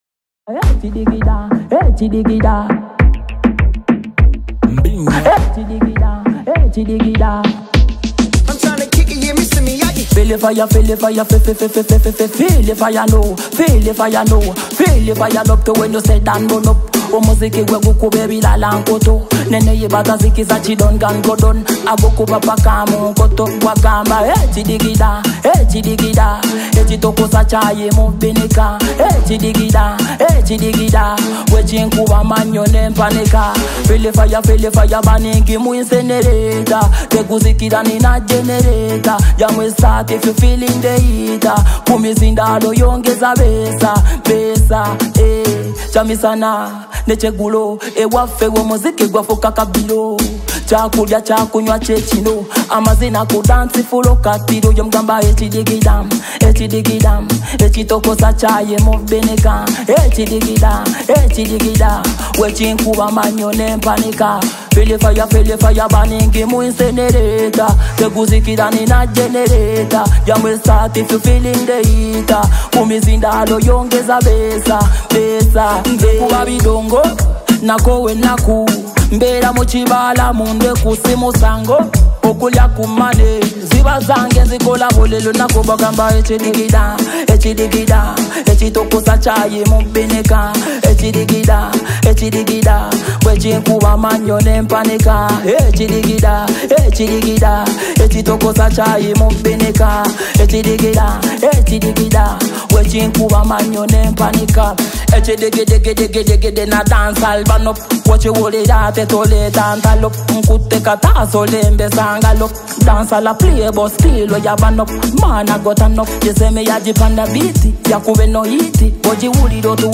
soulful and heartfelt music